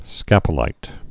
(skăpə-līt)